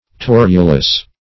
Torulous \Tor"u*lous\, a.
torulous.mp3